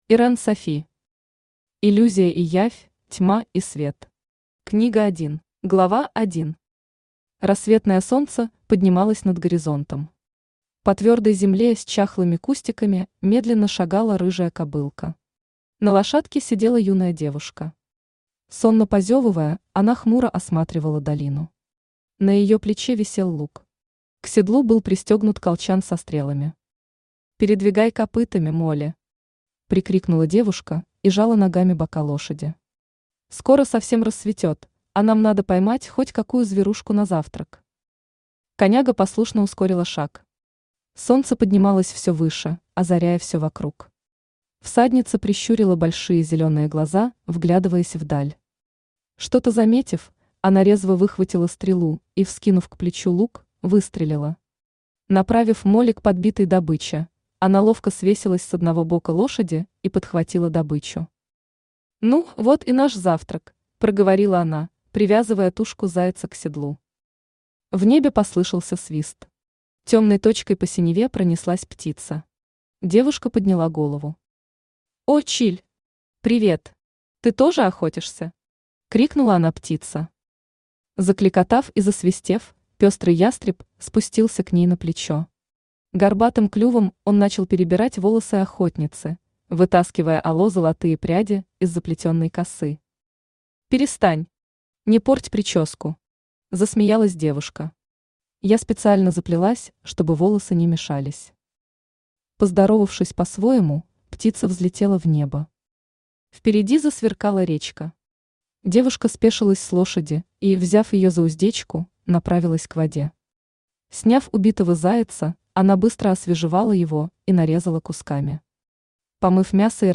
Aудиокнига Иллюзия и явь, Тьма и свет. Книга 1 Автор Ирен Софи Читает аудиокнигу Авточтец ЛитРес.